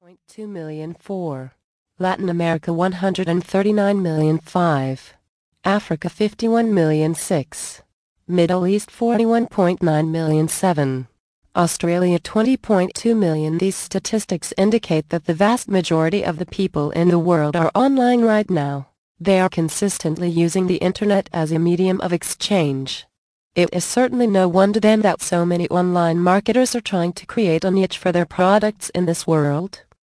Indispensable Almanac of Internet Marketing mp3 Audio Book 2